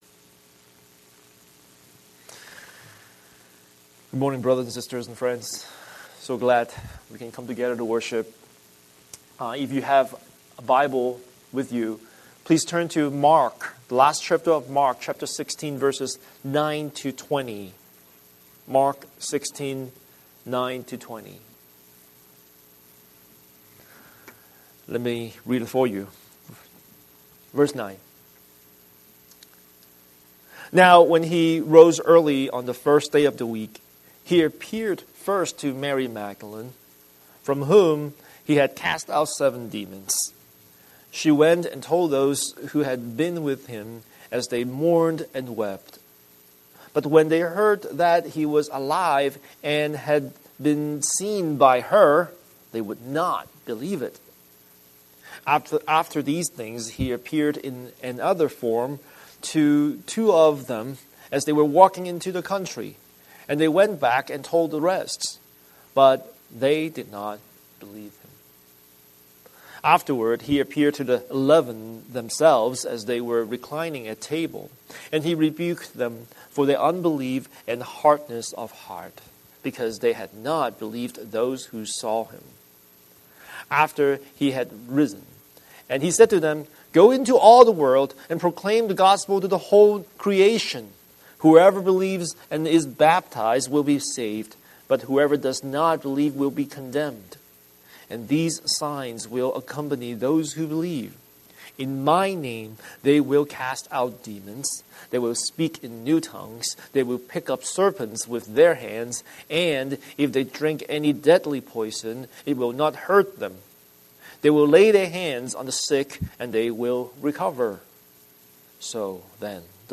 Scripture: Mark 16:9-20 Series: Sunday Sermon